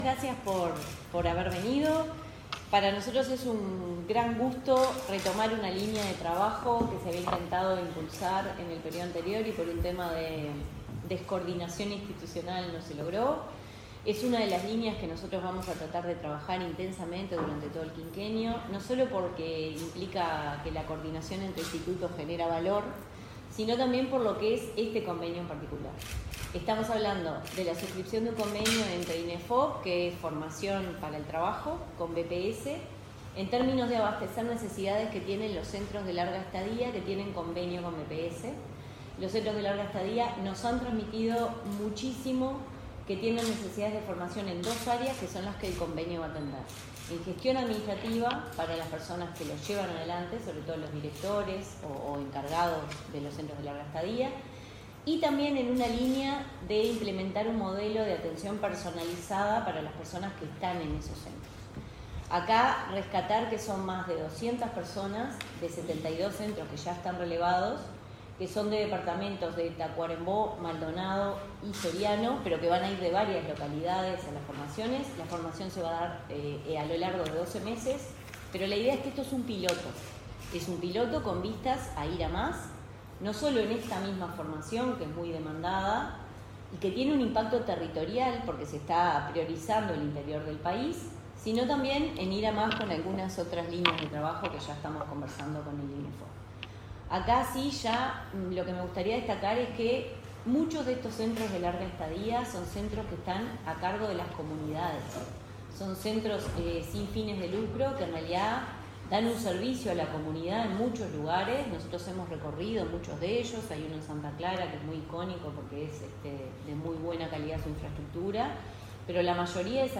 Palabras de autoridades del BPS e Inefop
La presidenta del BPS, Jimena Pardo, y el director general del Instituto Nacional de Formación Profesional (Inefop), Miguel Venturiello, se expresaron acerca de los detalles del acuerdo suscripto entre ambas instituciones, que permitirá la capacitación de 210 funcionarios de 72 centros de larga estadía en convenio con el BPS, ubicados en Maldonado, Soriano y Tacuarembó.